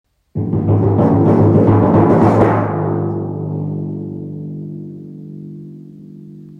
Patarumpu
Timpani.m4a